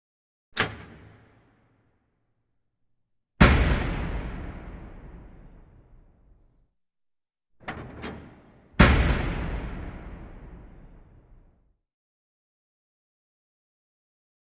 Open/Slam | Sneak On The Lot
Metal Door Open And Slam Hard In Reverberant Hall.